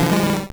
Cri de Tadmorv dans Pokémon Or et Argent.